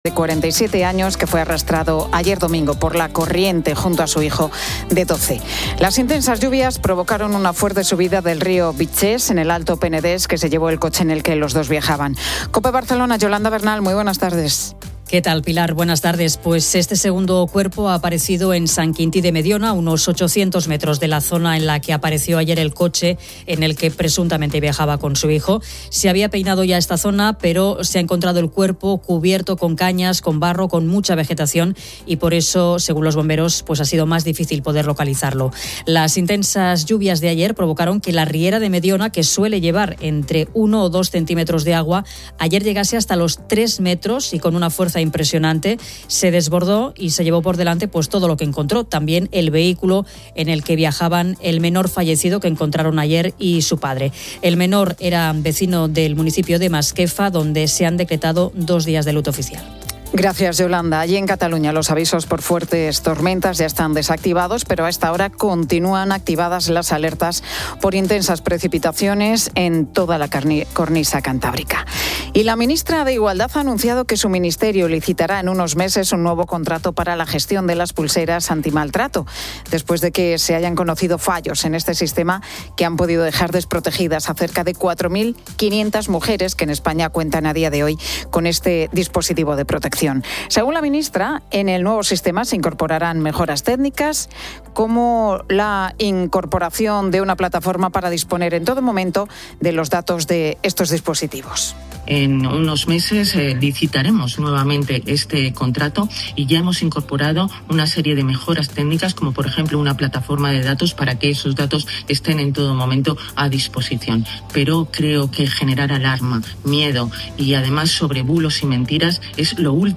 Comienza el otoño oficialmente. Los oyentes comparten qué les marca el fin del verano.
La Tarde 15:00H | 22 SEP 2025 | La Tarde Pilar García Muñiz cuenta que en París han hallado un Rubens perdido desde 1613.